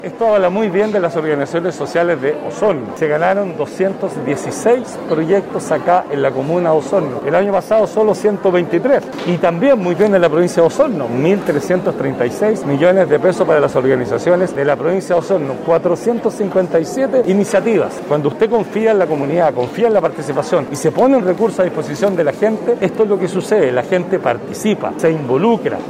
Hasta el Mercado Municipal de Osorno llegaron beneficiarios y autoridades  para la firma de los convenios entre el Gobierno Regional y los representantes de las 216 iniciativas beneficiarias del fondo 7% FNDR 2022 que sumaron $666 millones. El gobernador regional, Patricio Vallespín, destacó el aumento de proyectos ganadores en Osorno para este año, además de la participación ciudadana.